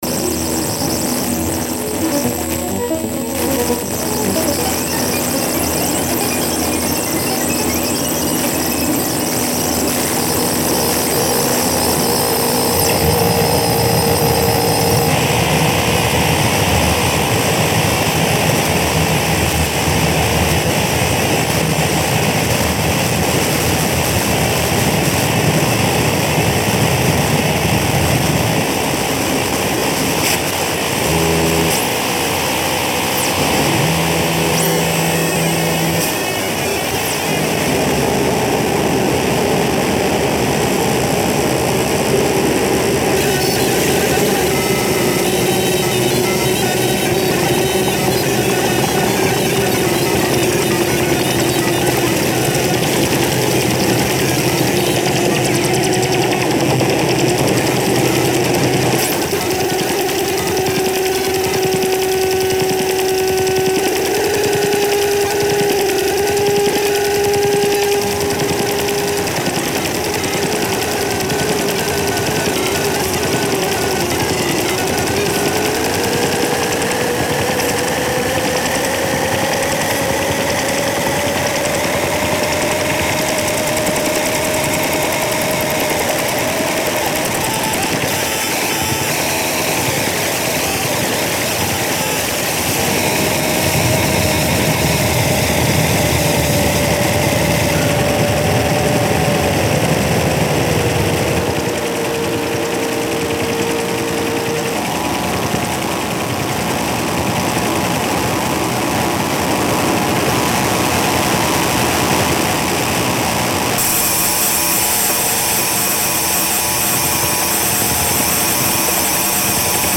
full set [beginning gets cut off]